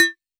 RDM_Copicat_SY1-Perc01.wav